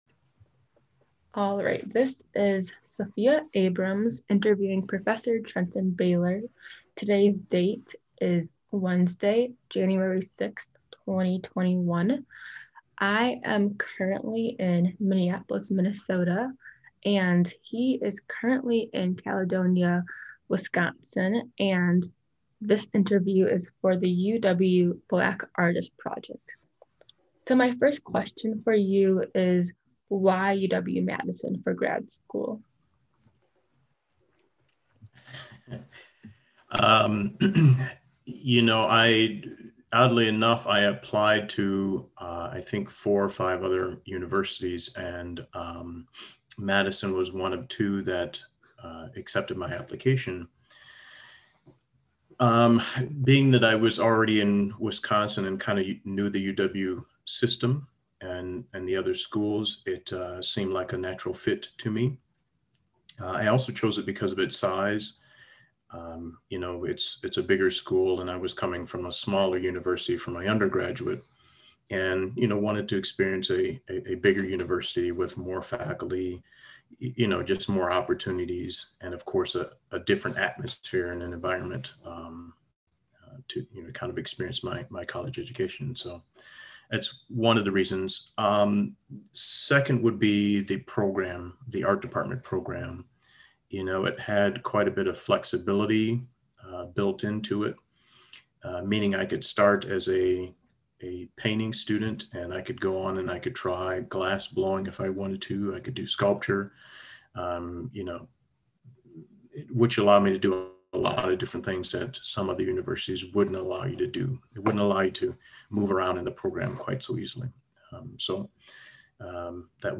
Oral History Program